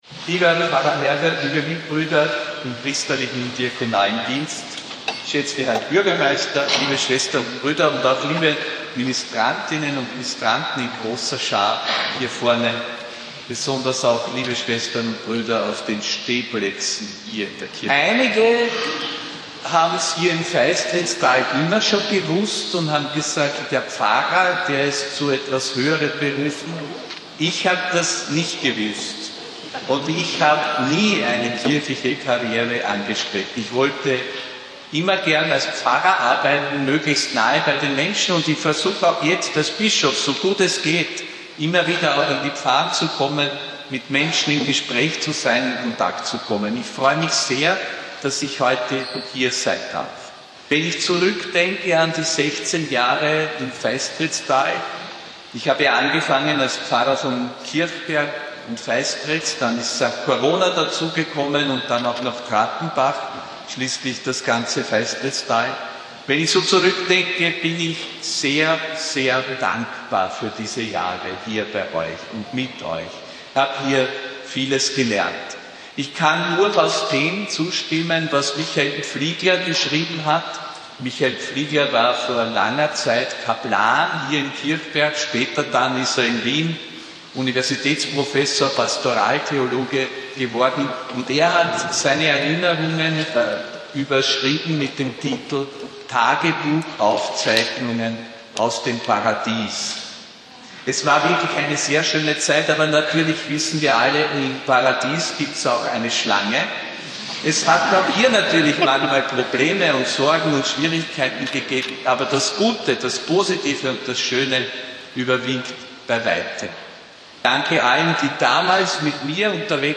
Predigt von Josef Grünwidl bei der Bischofsmesse in Kirchberg/Wechsel (01.03.2026)
Kirchberg am Wechsel, am 1. März 2026.